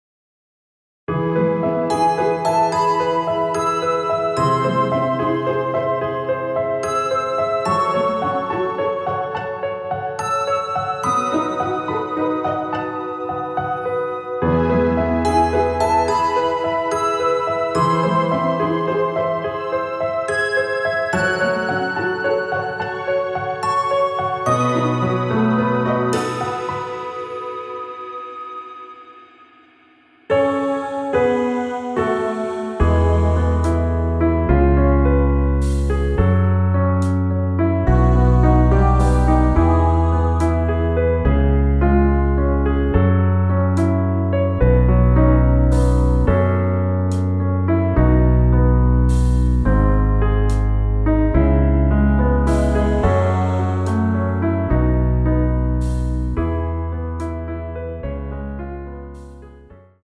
엔딩이 페이드 아웃이라 노래하기 편하게 엔딩을 만들어 놓았습니다.(멜로디 MR 미리듣기 확인)
앞부분30초, 뒷부분30초씩 편집해서 올려 드리고 있습니다.
중간에 음이 끈어지고 다시 나오는 이유는